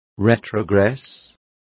Complete with pronunciation of the translation of retrogress.